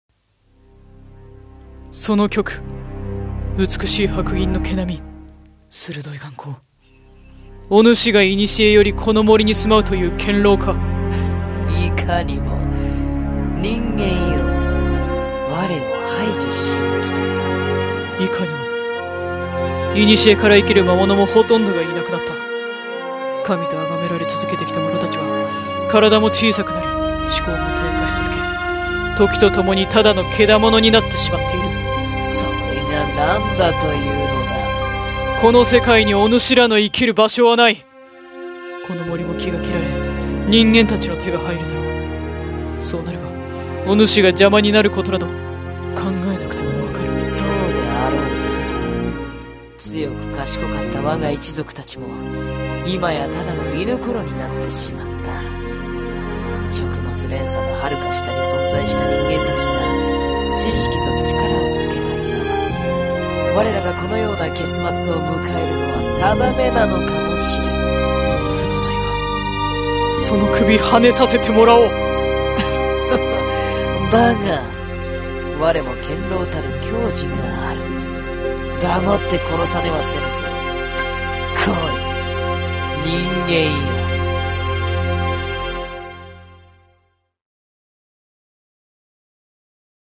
【声劇】進化と淘汰